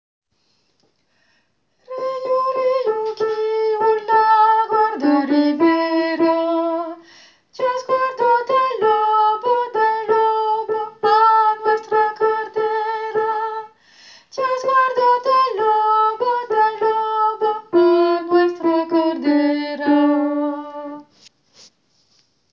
Tenor :
riu-tenor.wav